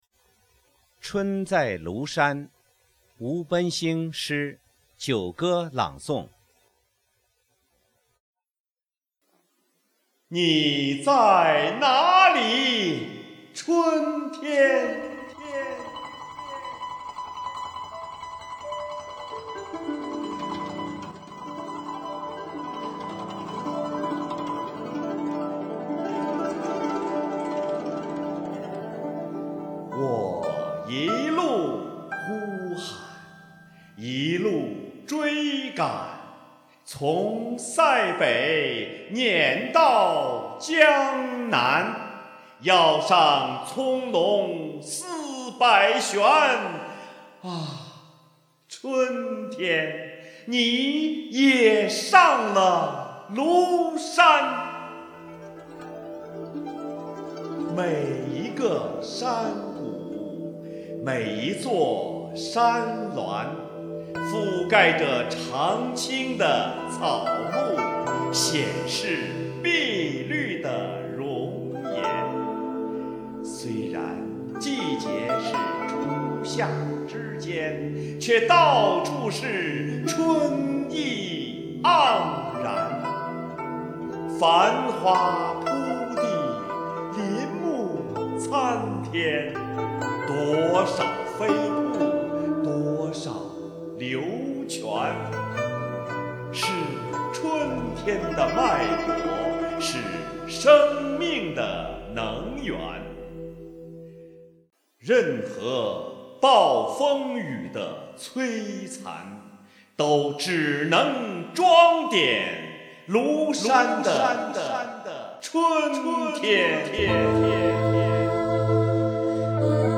吴奔星诗歌朗诵会：《春在庐山》